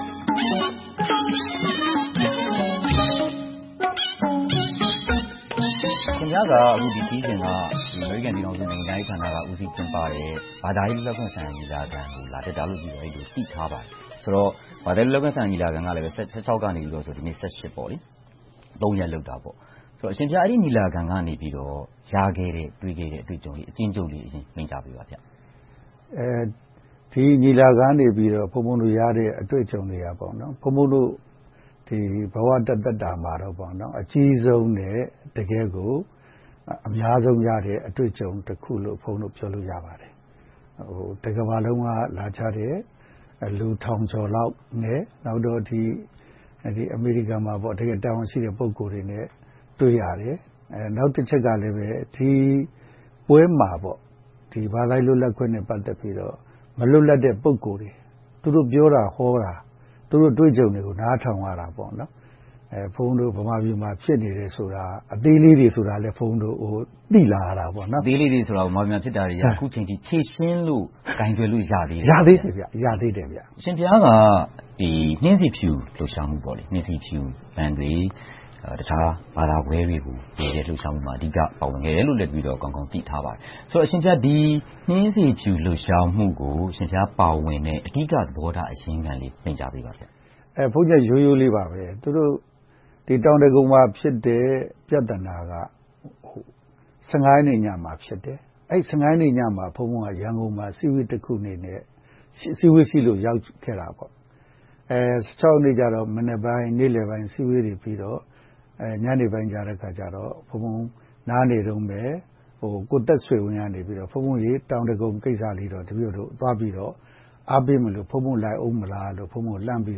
အာရှအလင်းရောင်ဆရာတော်နှင့် တွေ့ဆုံမေးမြန်းခန်း